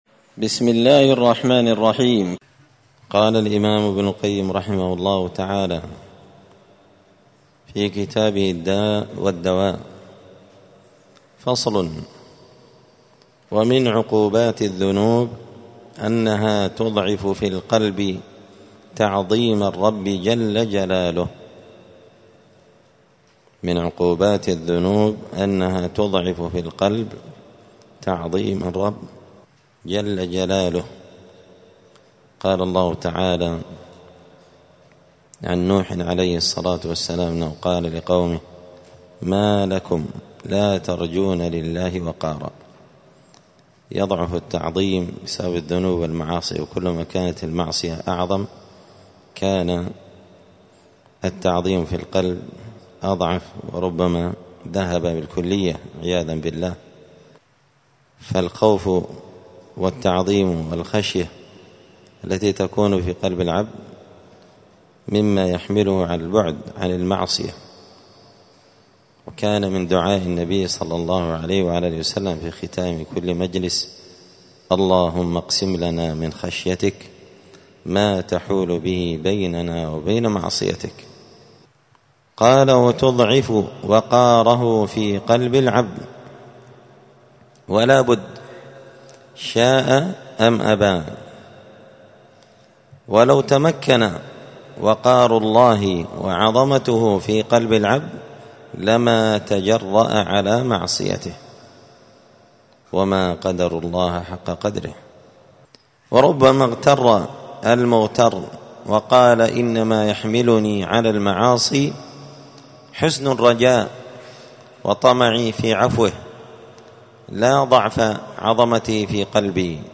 *الدرس الثاني والثلاثون (32) فصل من عقوبات الذنوب والمعاصي أنها تضعف في القلب تعظيم الرب*